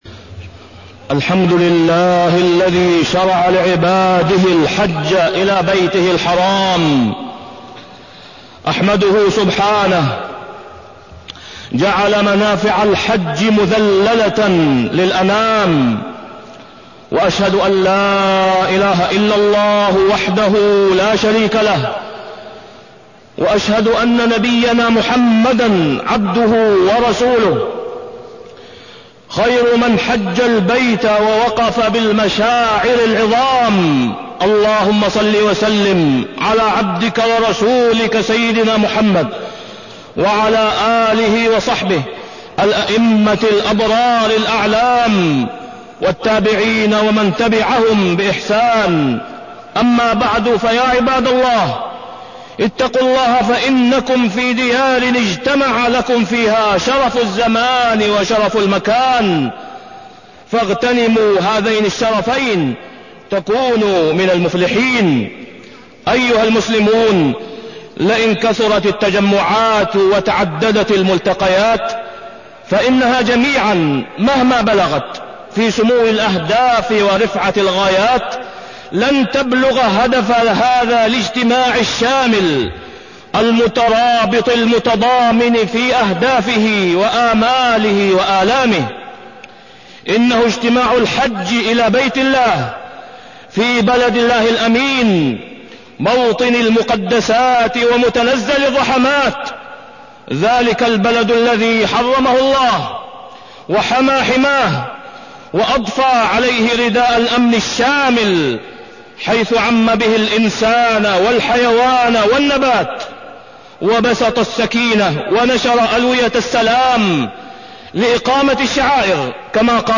تاريخ النشر ٣ ذو الحجة ١٤٢٢ هـ المكان: المسجد الحرام الشيخ: فضيلة الشيخ د. أسامة بن عبدالله خياط فضيلة الشيخ د. أسامة بن عبدالله خياط الحج والتوحيد